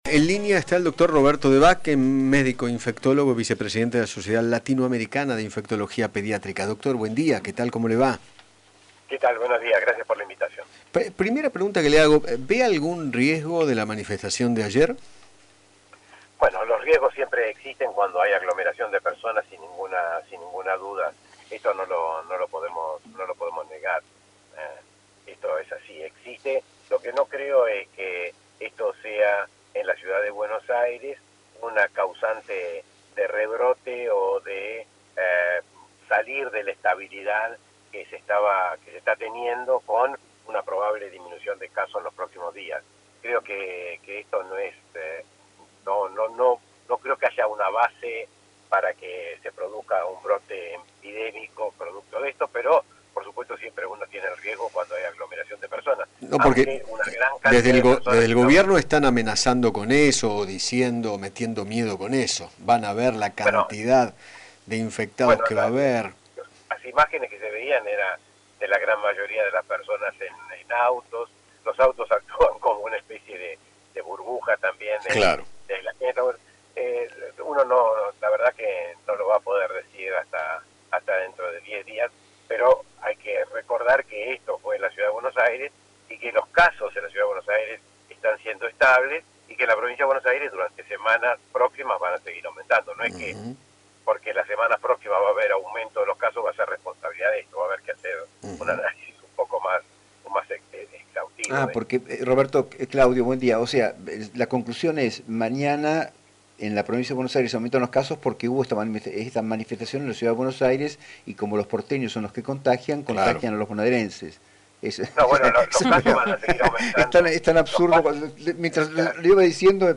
dialogó con Eduardo Feinmann sobre el banderazo de ayer y la posibilidad de que impacte en la curva de contagios de Covid-19. No obstante, aseguró que los casos van a seguir aumentando “porque ahora hay focos de contagio en el interior del país, que antes no había”.